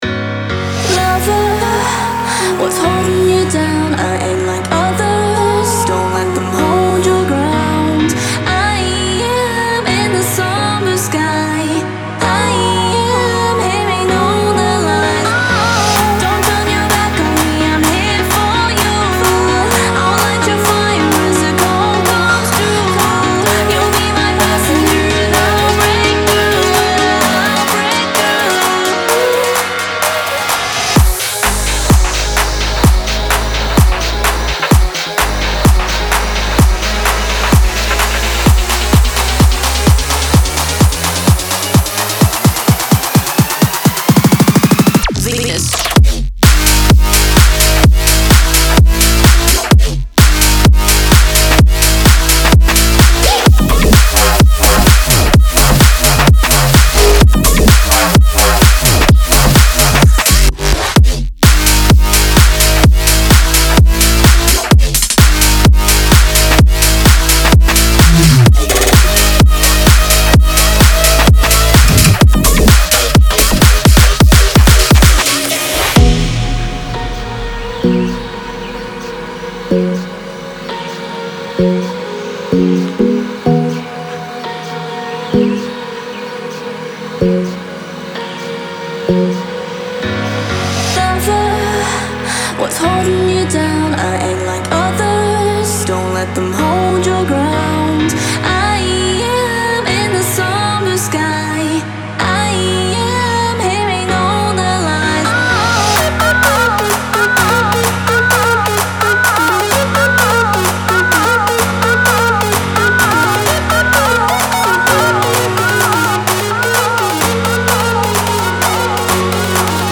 House, Energetic, Mysterious, Quirky, Angry